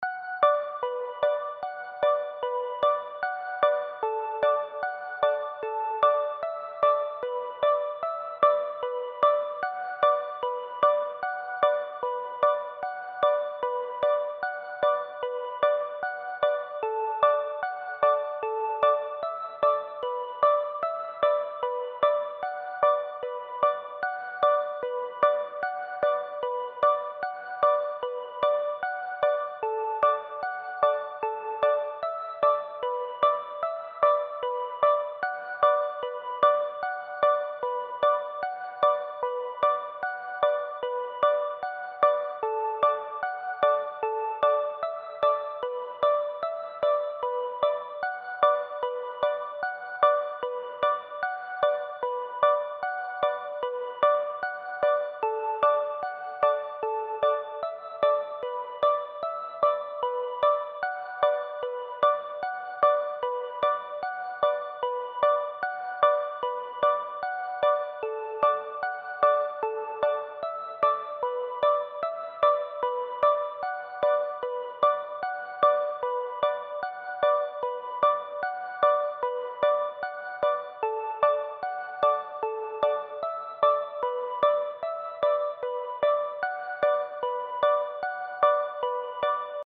🔹 50 Custom Serum Presets crafted for wave pop, ambient electronica, and deep emotional beats.
These presets feel like water: fluid, lush, and endlessly immersive.
Organic Plucks & Bells – Clean yet soulful, ideal for toplines and arps